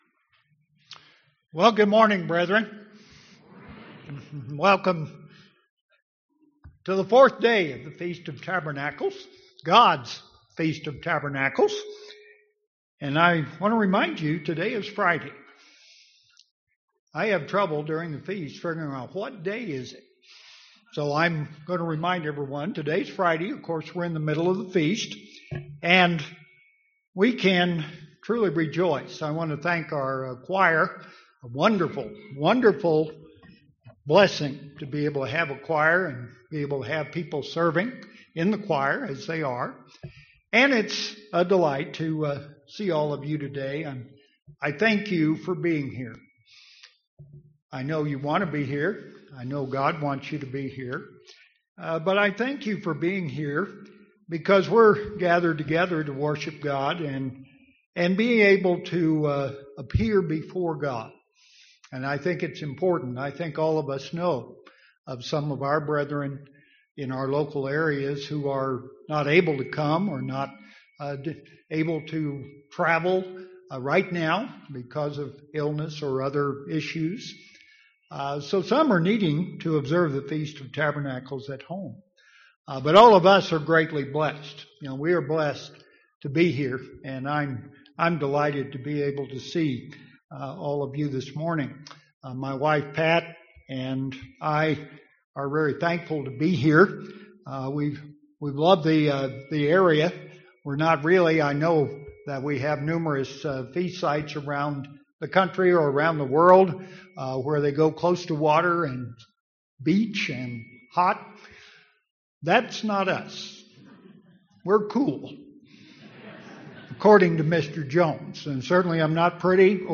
This sermon was given at the Steamboat Springs, Colorado 2021 Feast site.